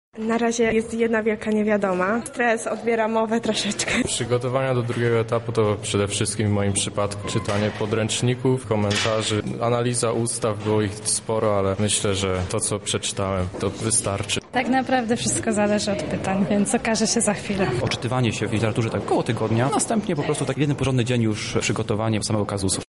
Uczestnicy opowiedzieli o swoich przygotowaniach do obu części zmagań.
Finaliści